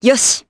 Hilda-Vox_Happy4_jp.wav